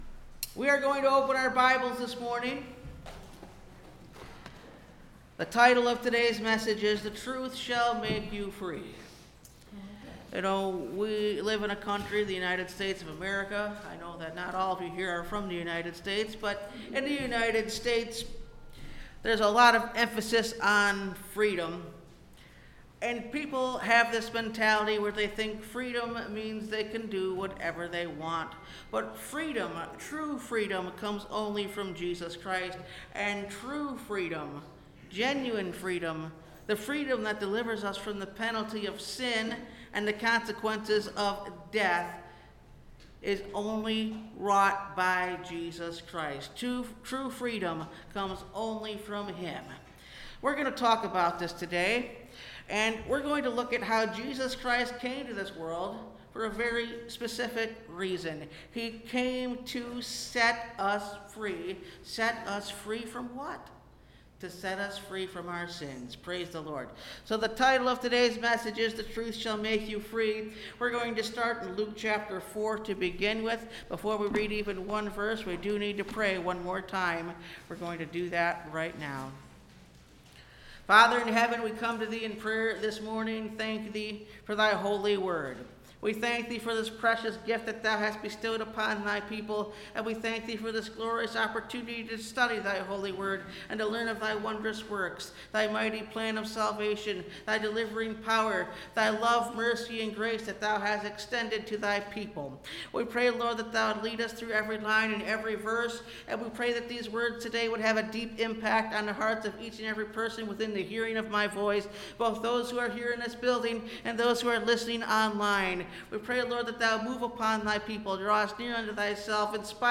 The Truth Shall Make You Free (Message Audio) – Last Trumpet Ministries – Truth Tabernacle – Sermon Library